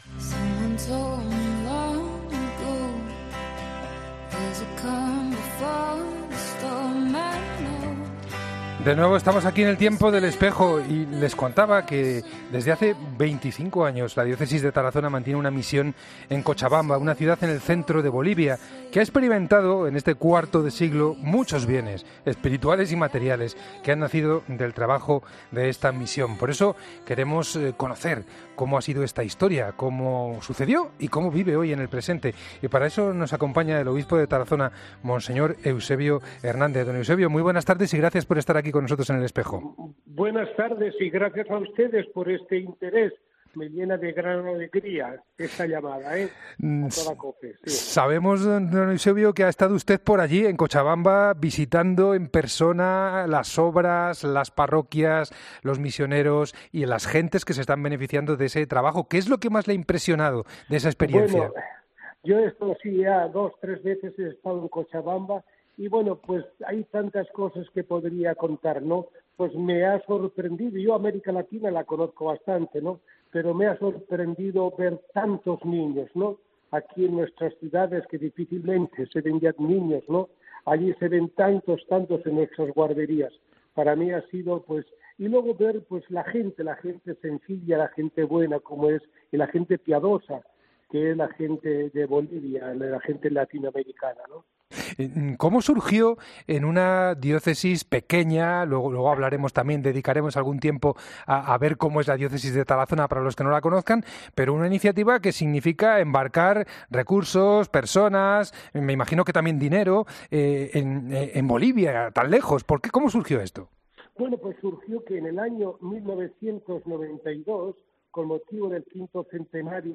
ESCUCHA LA ENTREVISTA COMPLETA | Mons. Eusebio Hernández, OAR, en 'El Espejo'